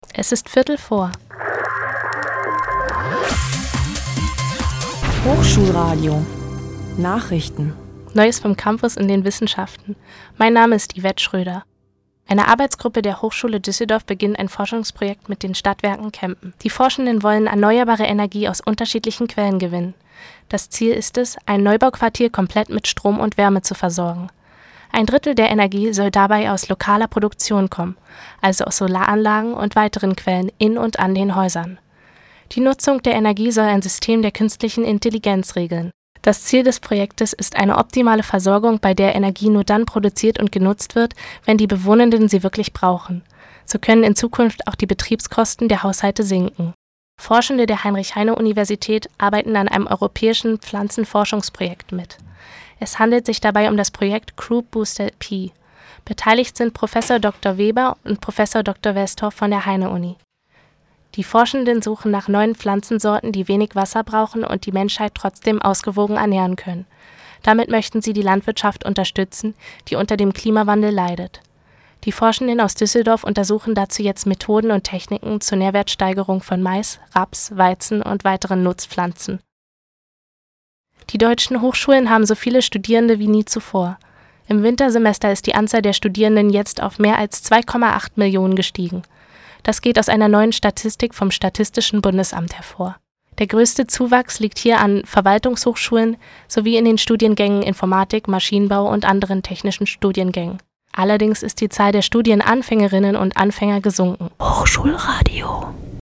[ campusnachrichten ]